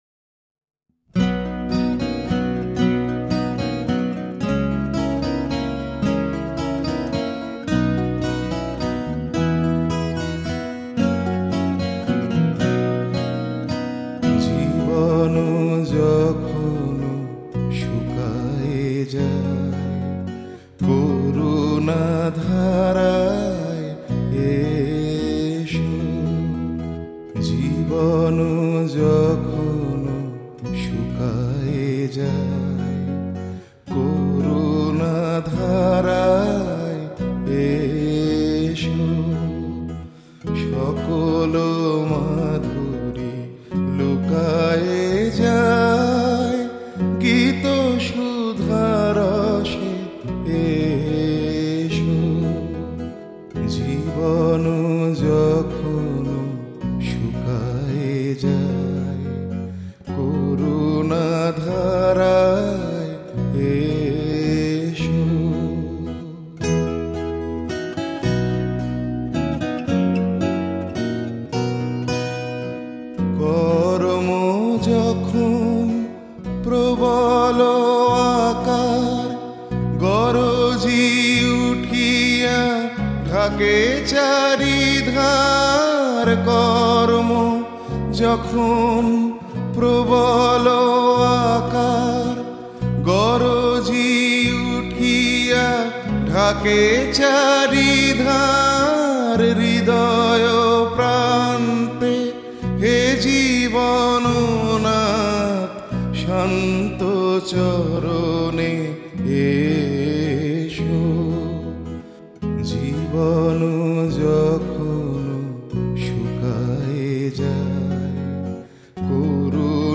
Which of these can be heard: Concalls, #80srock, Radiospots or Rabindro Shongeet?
Rabindro Shongeet